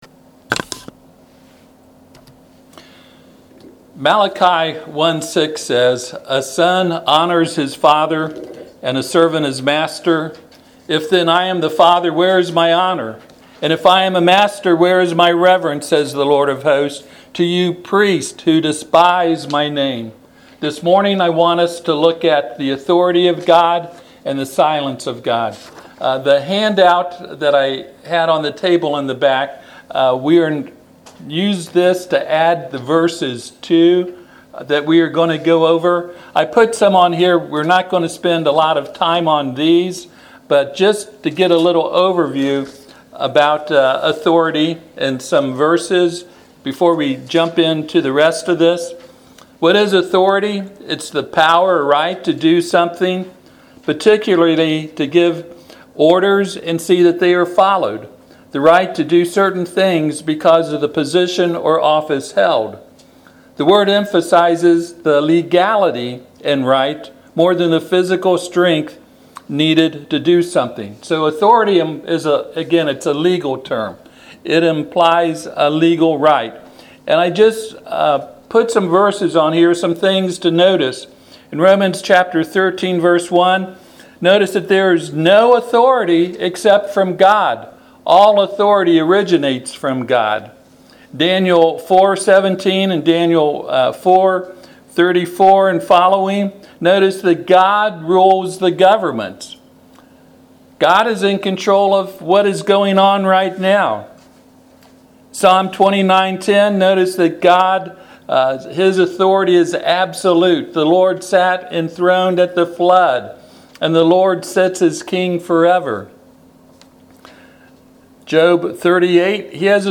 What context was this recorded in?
Passage: Matthew 21:23-27 Service Type: Sunday AM